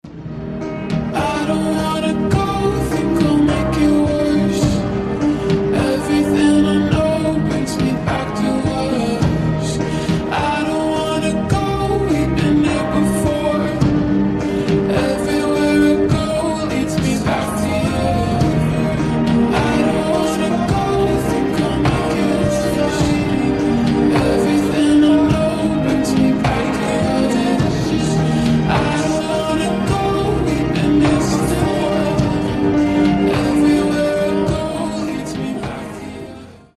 • Качество: 320, Stereo
грустные
медленные
ремиксы